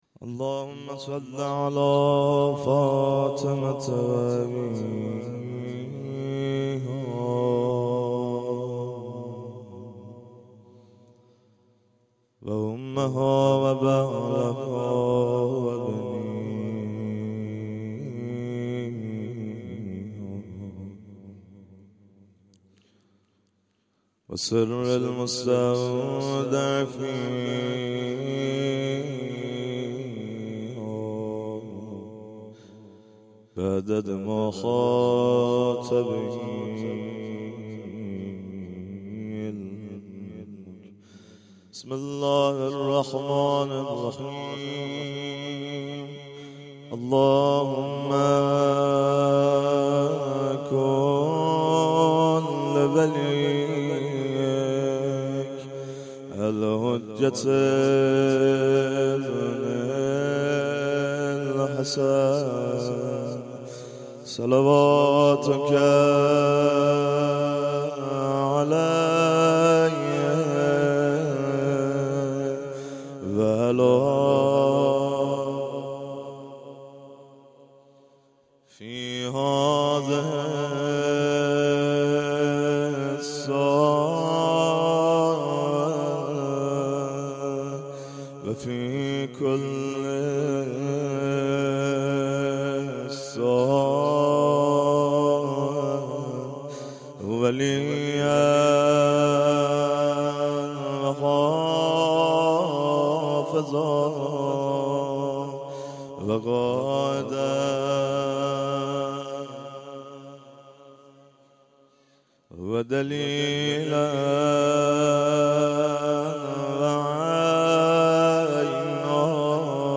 در این بخش می توانید فایل صوتی بخش های مختلف “یکصد و هفتاد و نهمین کرسی تلاوت و تفسیر قرآن کریم” شهرستان علی آباد کتول که در تاریخ ۲۹/خرداد ماه/۱۳۹۷ برگزار شد را دریافت نمایید.
قرائت فرازهایی از دعای توسل